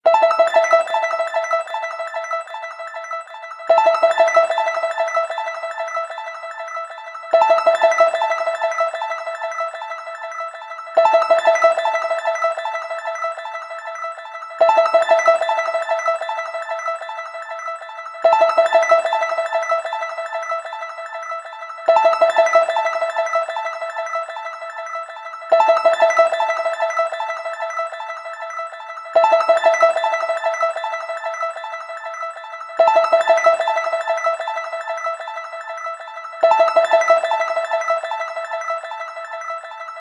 やまびこのような残響系反響音。